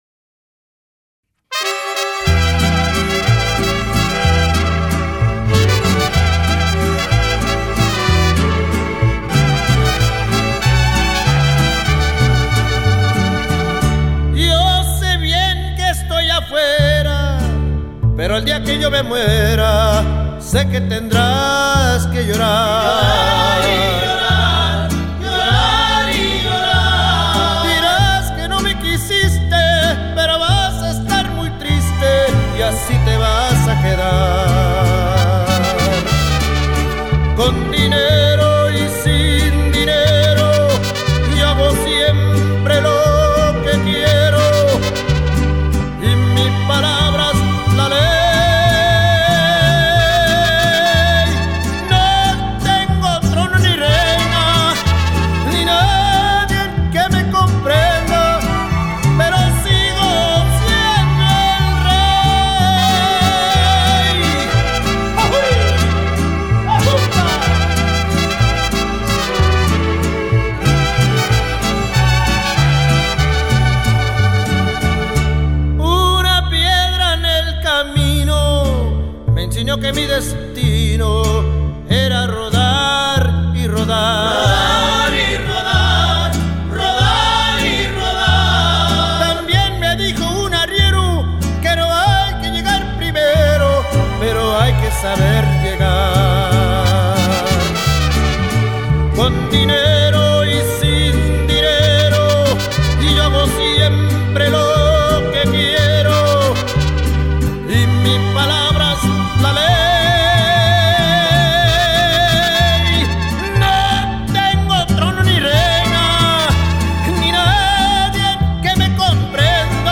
Latin & World Music